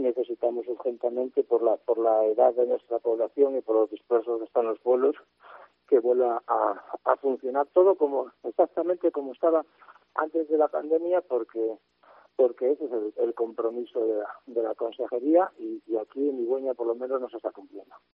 AUDIO: Escucha aquí al alcalde de Igüeña, Alider Presa